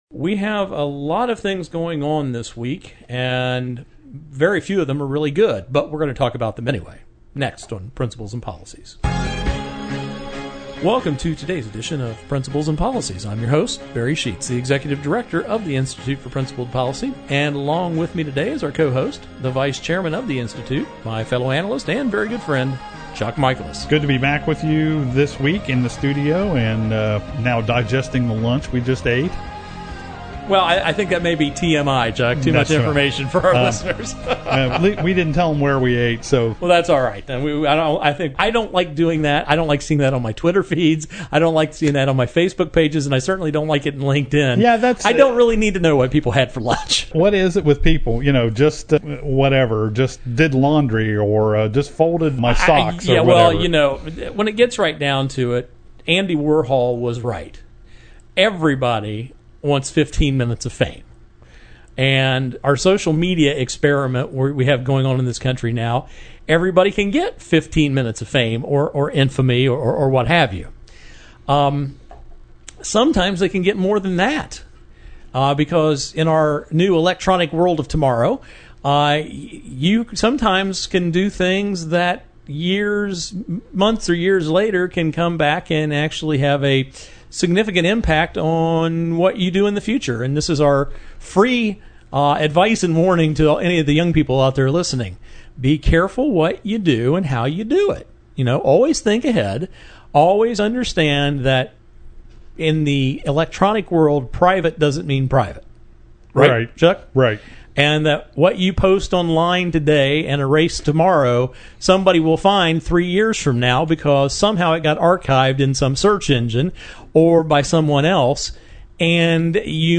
Our Principles and Policies radio show for Saturday August 22, 2015.